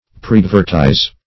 Search Result for " preadvertise" : The Collaborative International Dictionary of English v.0.48: Preadvertise \Pre*ad`ver*tise"\, v. t. To advertise beforehand; to preannounce publicly.